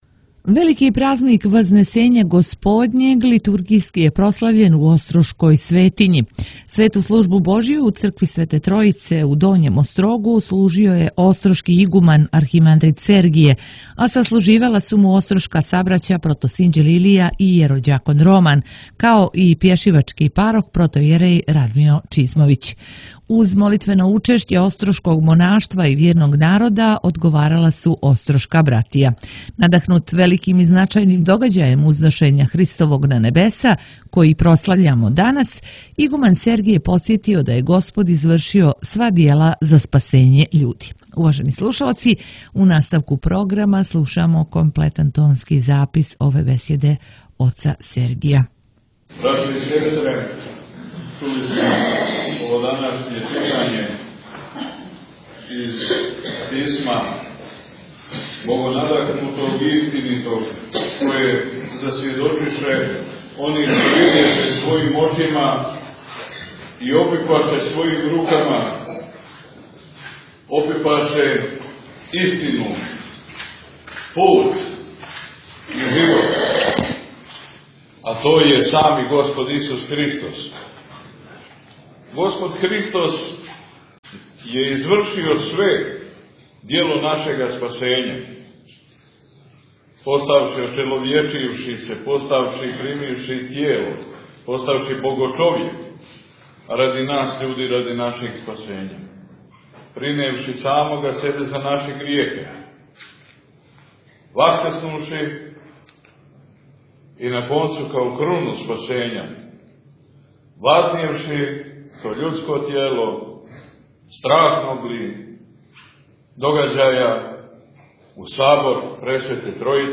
Велики празник Вазнесења Господњег – Спасовдан прослављен је литургијски у острошкој светињи у четвртак 2. јуна 2022. љета Господњег, када […]